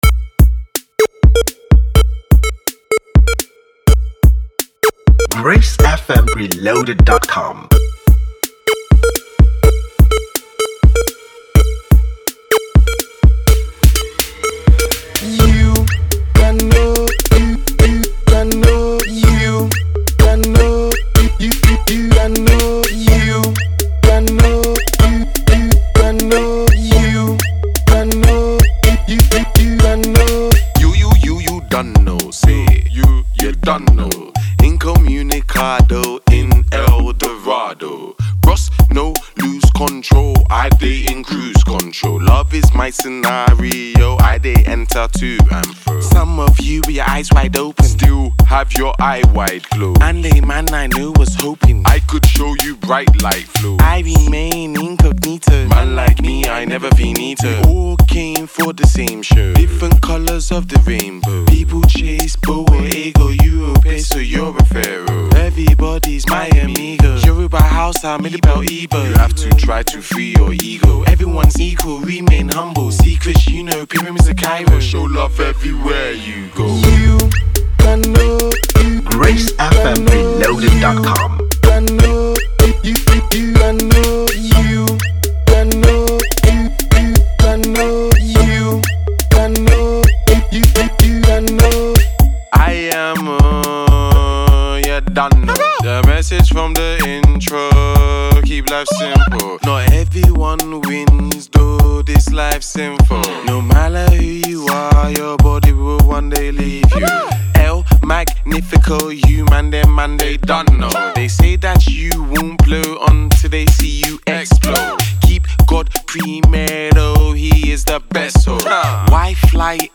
afrocentric
was recorded, mixed and mastered at the Lokoja studios